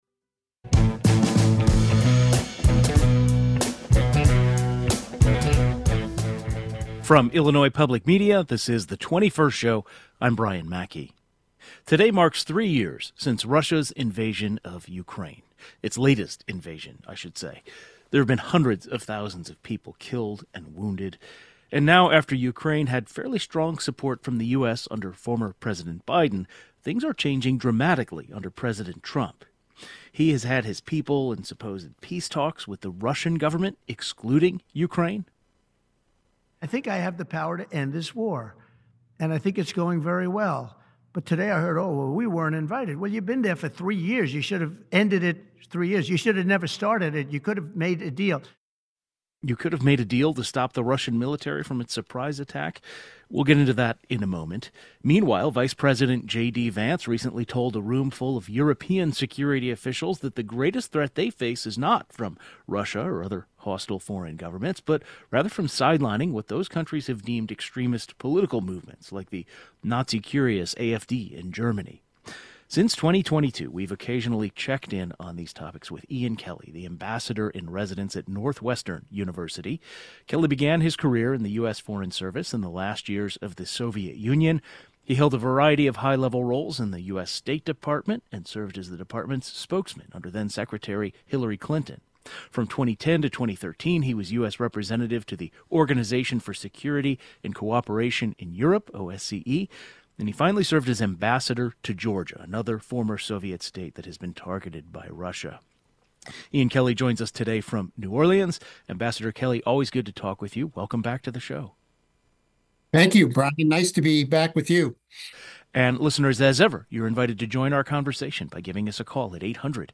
Guest: Ambassador Ian Kelly Ambassador in residence • Northwestern University - Former US Ambassador to Georgia US.